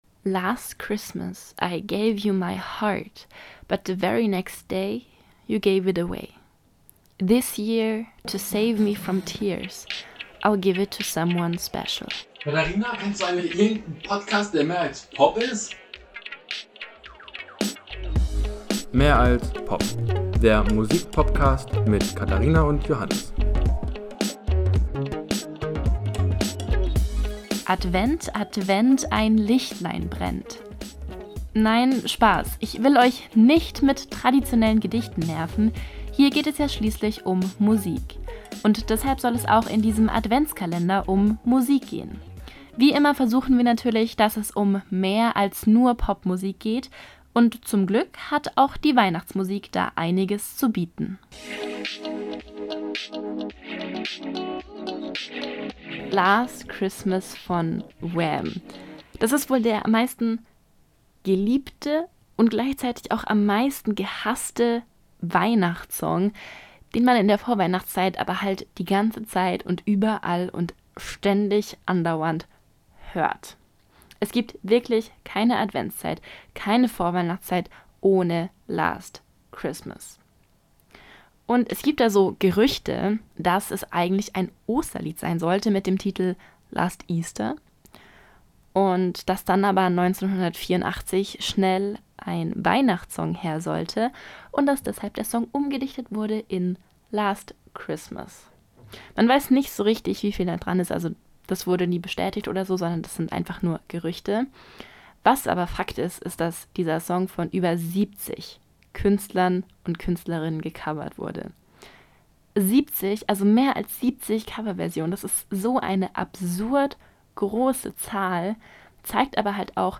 Die Musik für Intro und Outro ist von WatR.